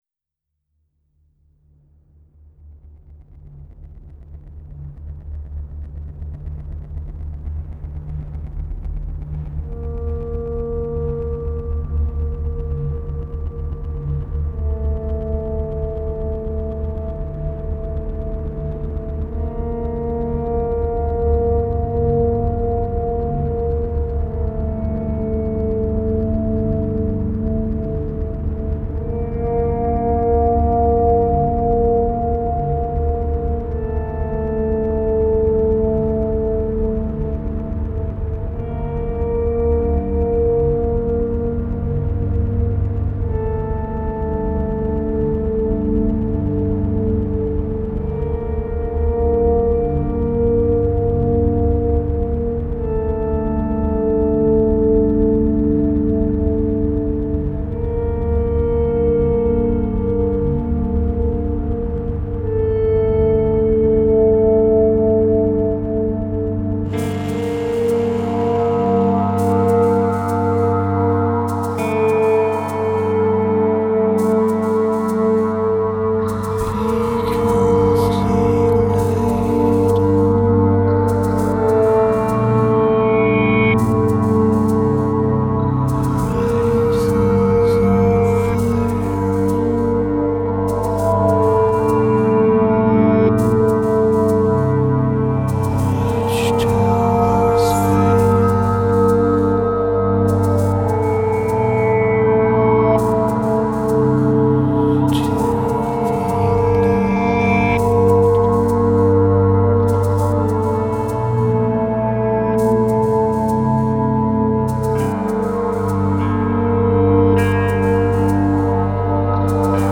fantomatique et tout en tension.